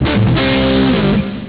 guitare.zip 1.43 Mo 23 mélodies de folie à la guitare !